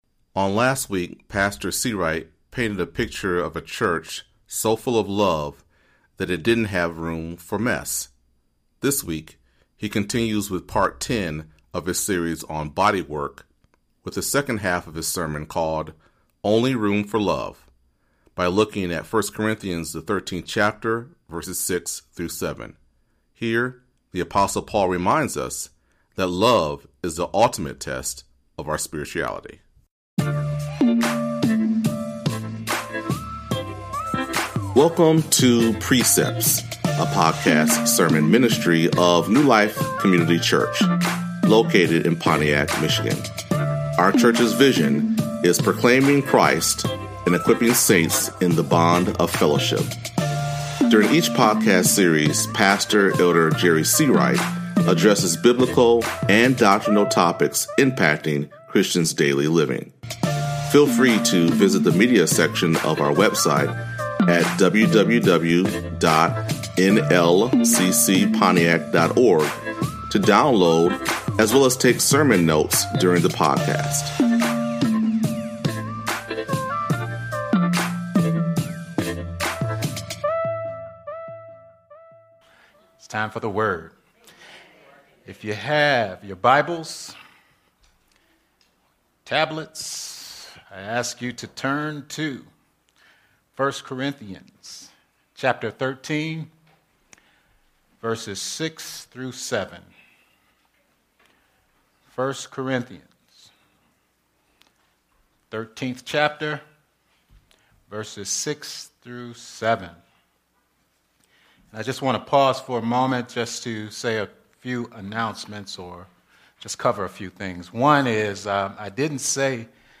This week, he continues with Part 10 of his series on “Body Work” with the second half of his sermon called “Only Room For Love” by looking at 1 Corinthians 13:6-7. Here, the Apostle Paul reminds us that love is the ultimate test of our spirituality.